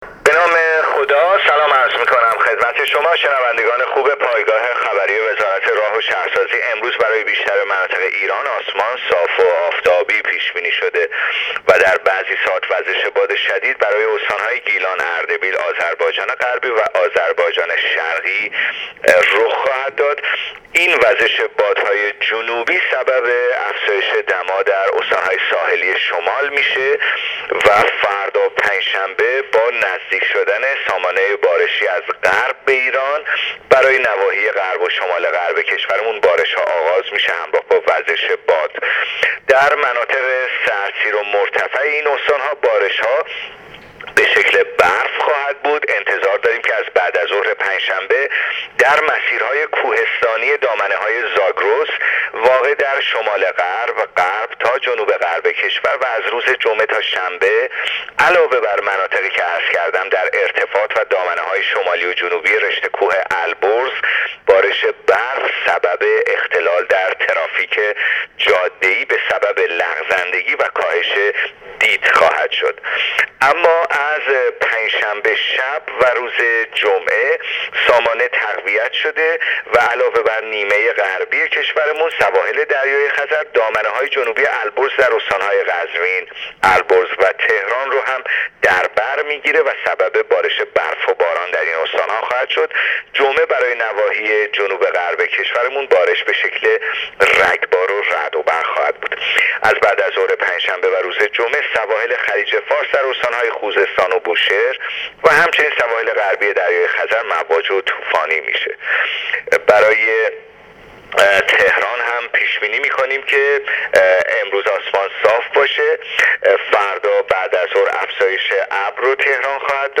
کارشناس سازمان هواشناسی در گفت و گو با راديو اينترنتی پايگاه خبری آخرين وضعيت هوا را تشریح کرد.